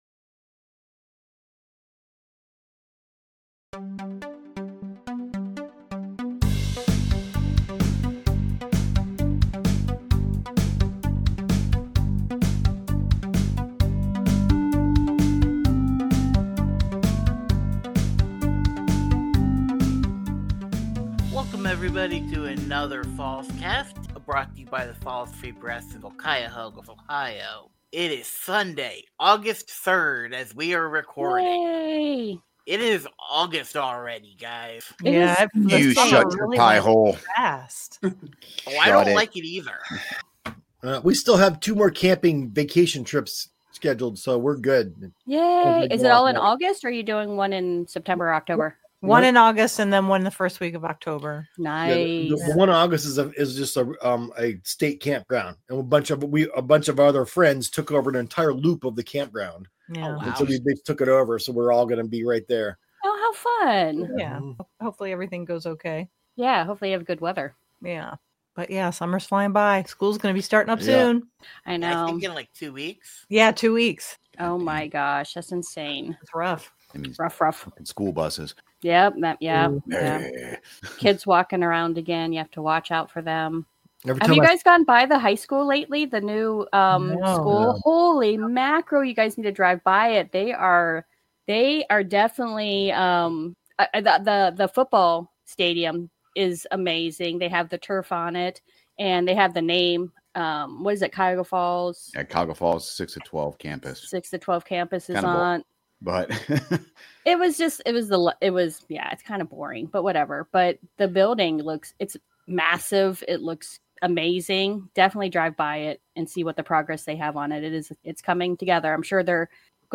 This episode’s discussion includes: